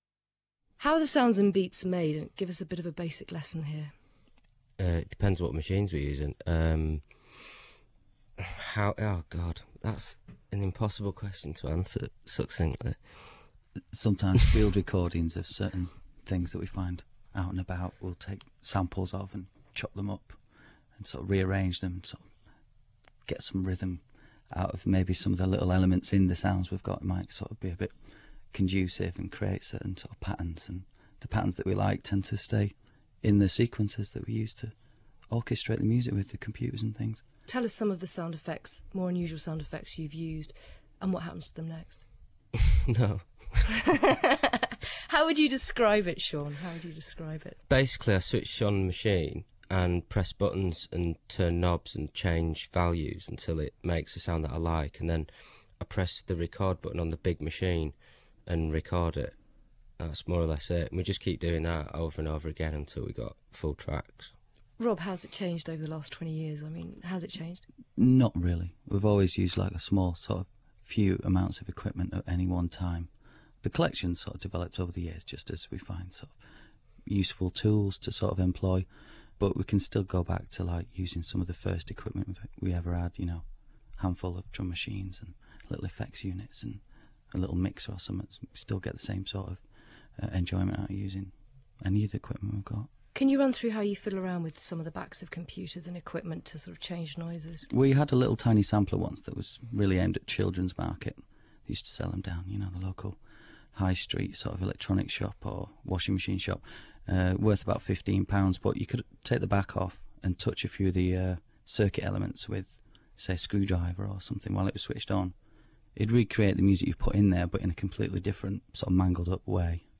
Autechre - Interview on Today Programme (2008)